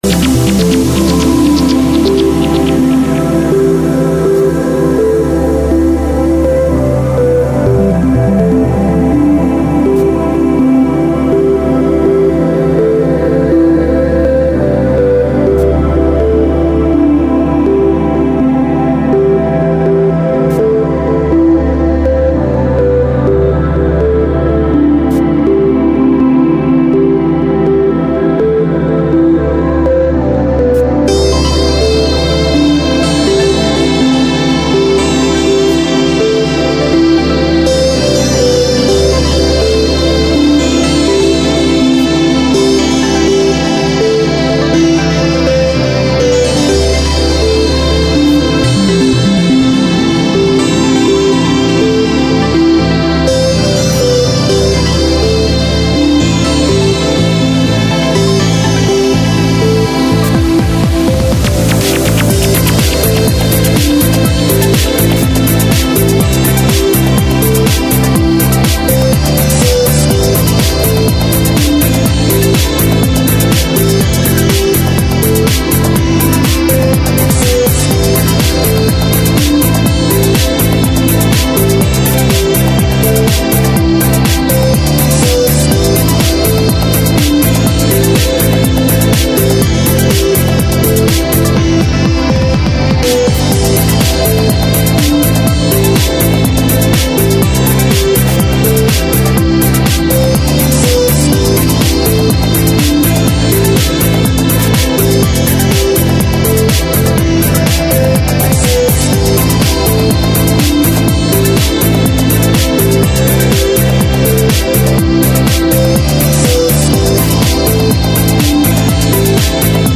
Танцевальный трек.